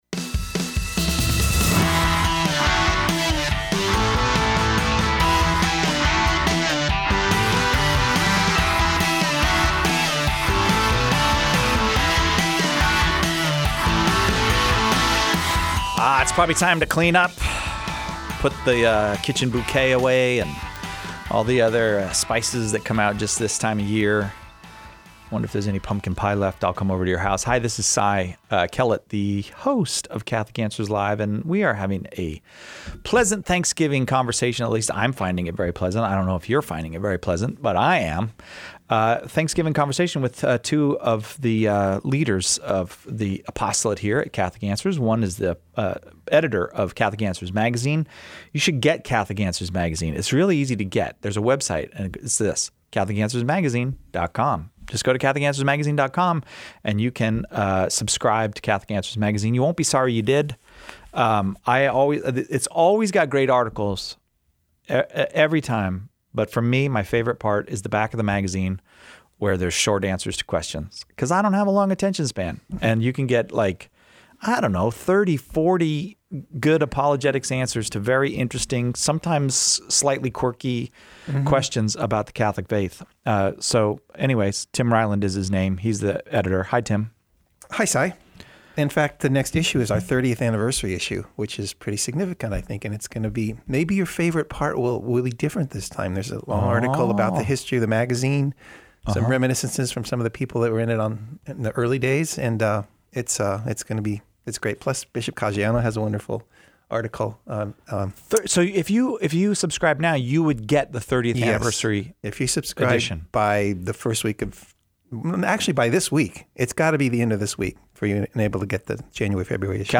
A lighthearted conversation